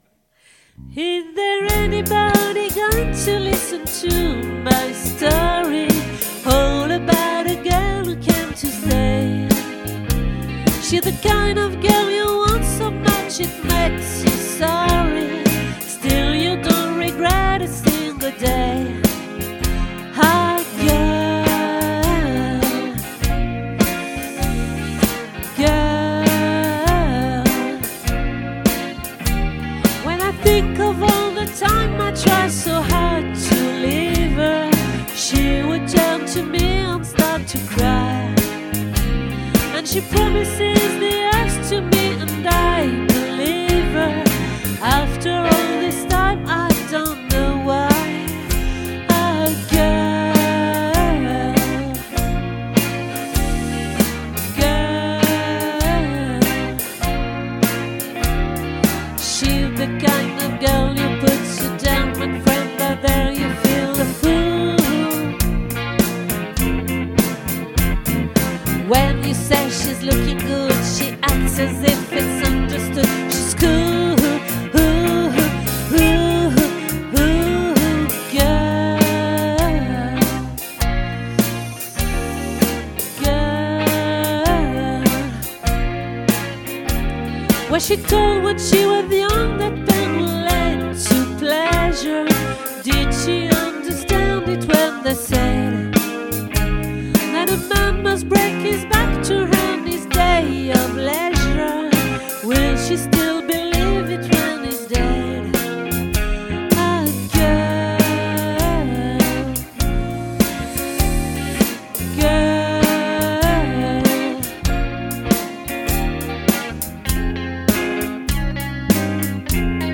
🏠 Accueil Repetitions Records_2024_01_29